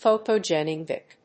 音節phòto・engráving
アクセント・音節phòto・engráving